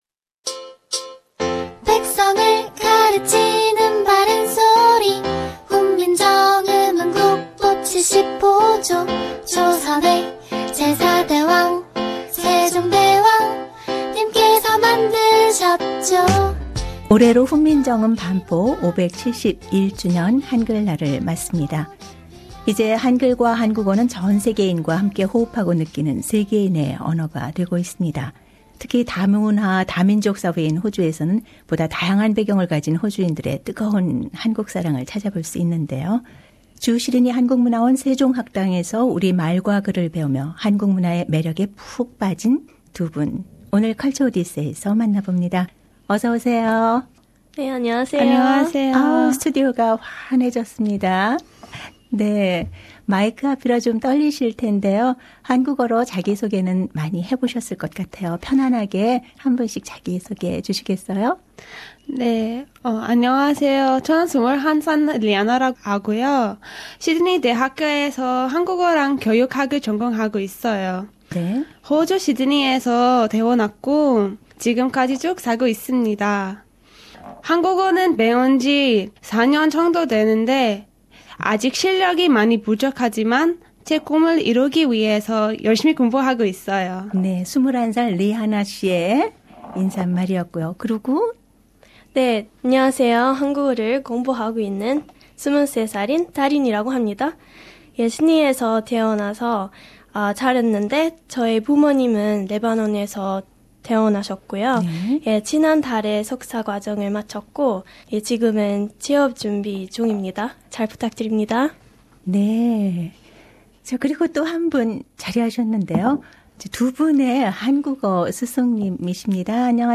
상단의 다시 듣기(Podcast)를 통해 인터뷰 내용을 확인하세요.